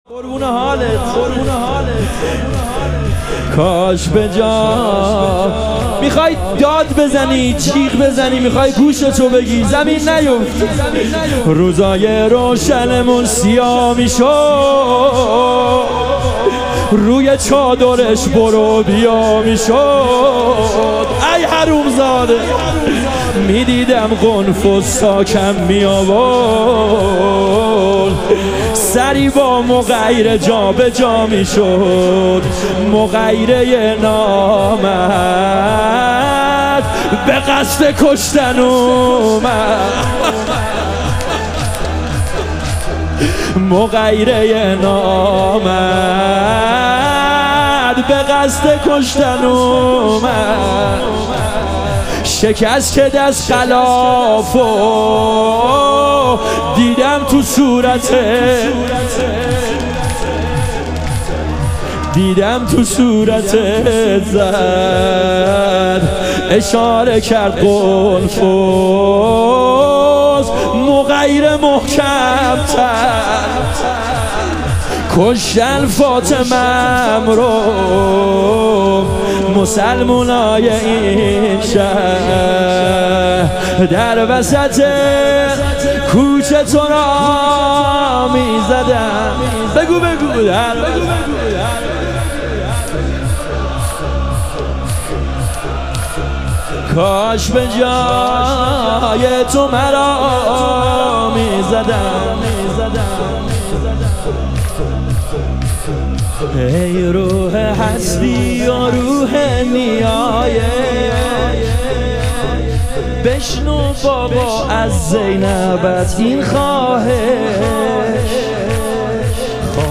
ایام فاطمیه اول - تک